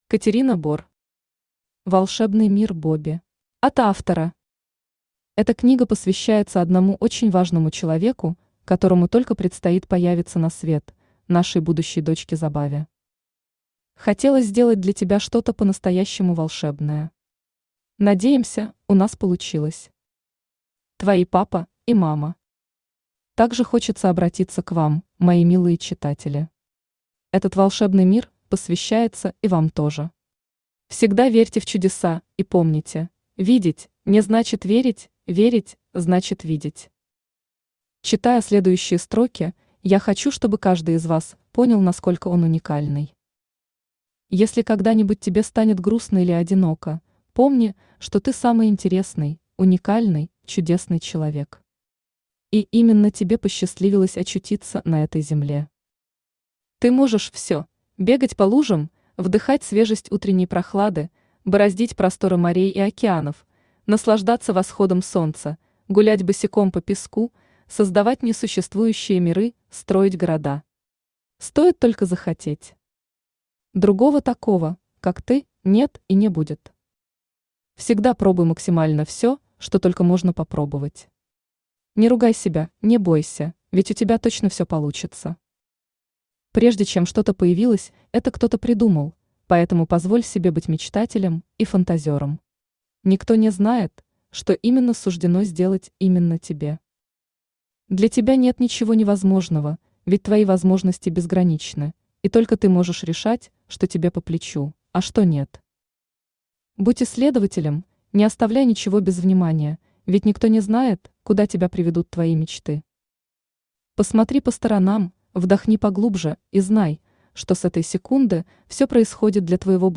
Aудиокнига Волшебный мир Бобби Автор Катерина Бор Читает аудиокнигу Авточтец ЛитРес.